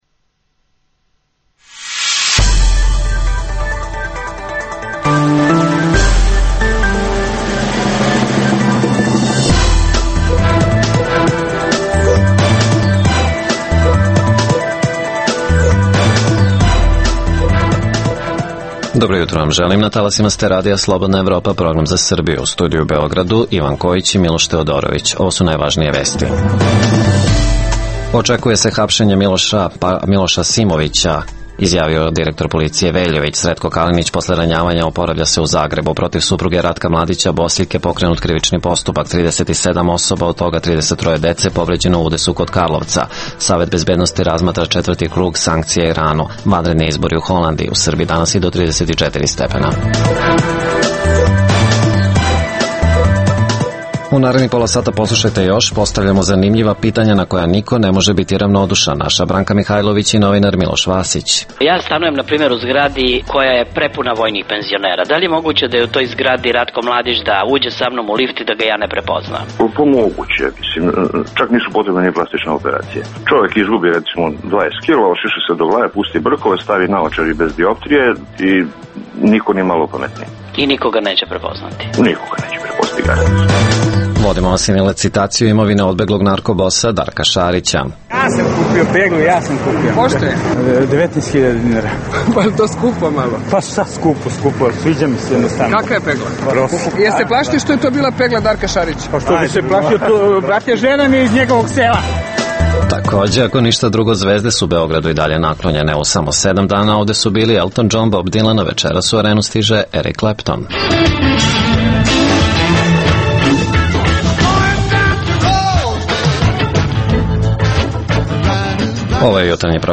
Beležimo i atmosferu na rasprodaji imovine narko bosa Darka Šarića. Posebno, najavljujemo večerašnji koncert Erika Kleptona u Beogradu.